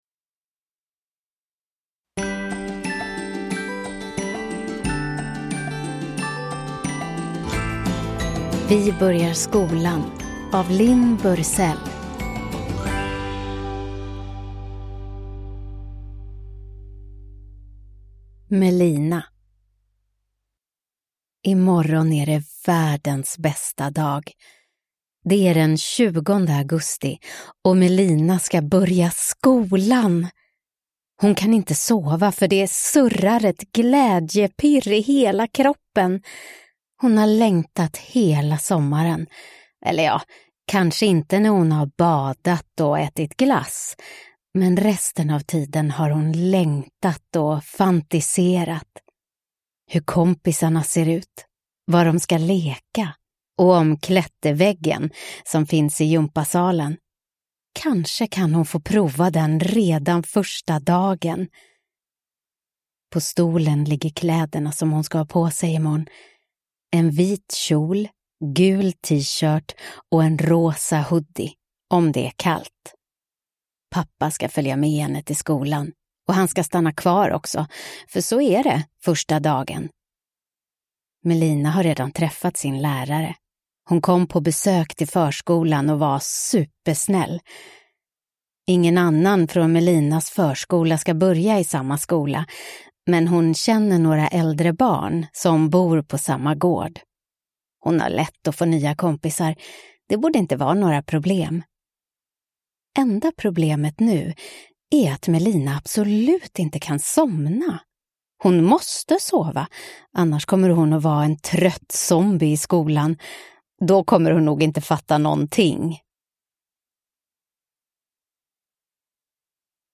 Vi börjar skolan – Ljudbok